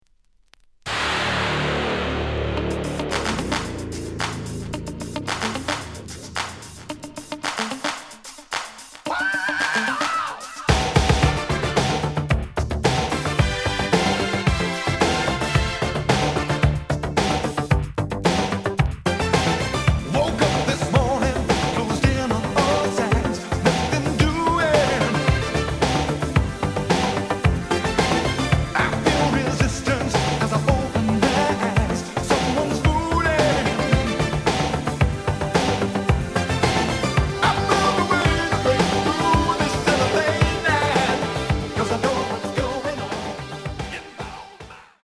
The audio sample is recorded from the actual item.
●Format: 7 inch
●Genre: Funk, 80's / 90's Funk